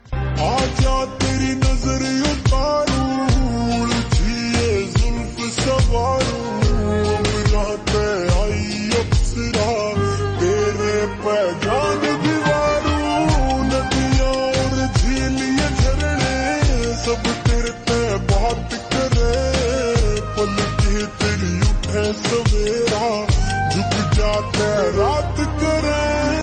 Haryanvi Songs
Slowed + Reverb